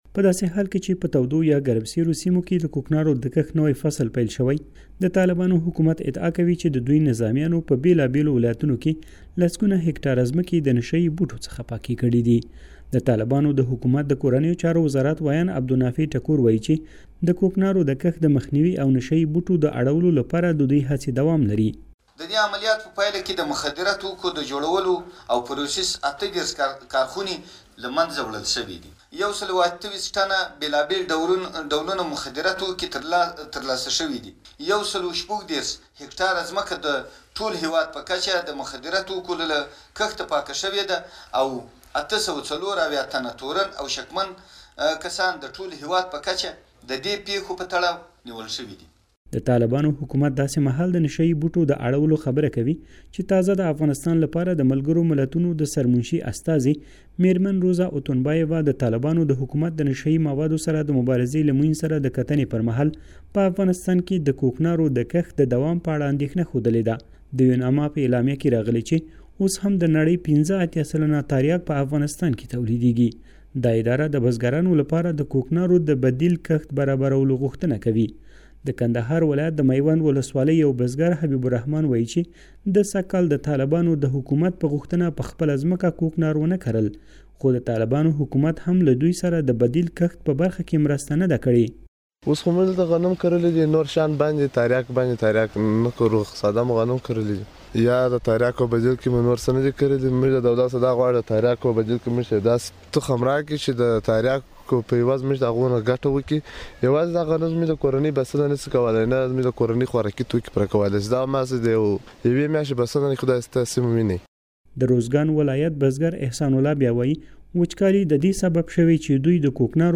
له نشه يي بوټو د ځمکو پاکولو راپور